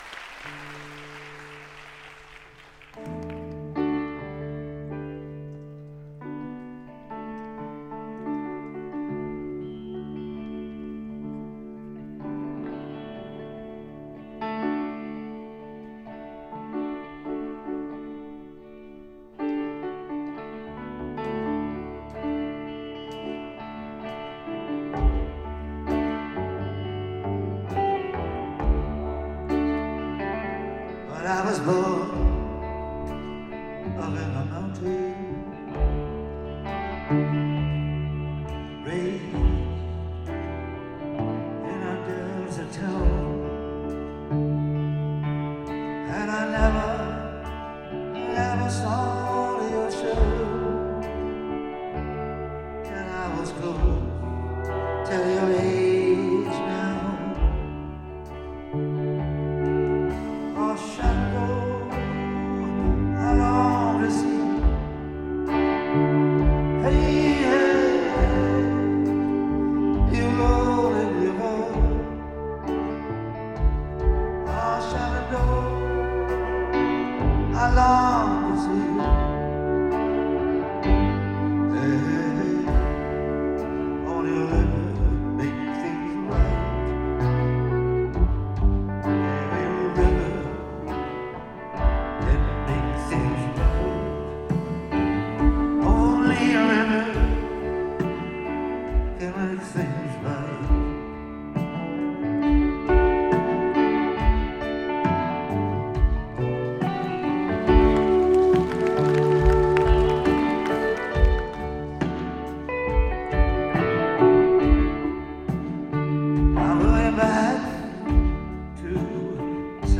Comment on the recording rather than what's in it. Less distant/muffled.